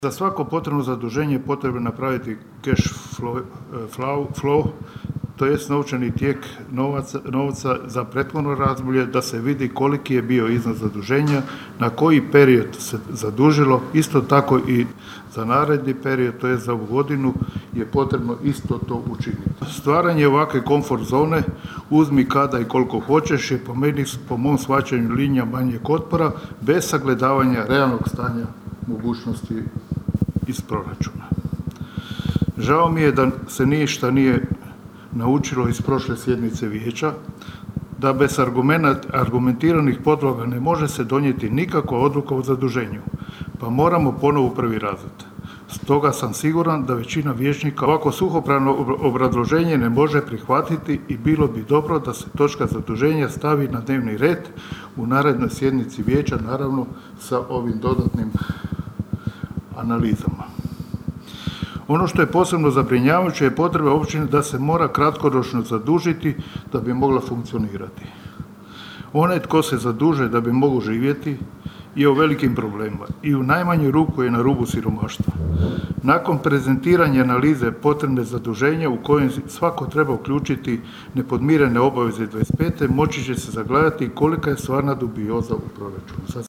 Na sinoćnjoj sjednici Općinskog vijeća Kršana vijećnici nisu donijeli odluku o milijun eura revolving kreditu, tražeći detaljniju analizu i obrazloženje.
Prijedlog odluke i samo obrazloženje je neodgovorno iz razloga jer ne sadrži dovoljno argumenata zašto se to čini“, rekao je nezavisni vijećnik Robert Stepčić: (